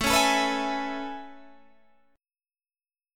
AM9 chord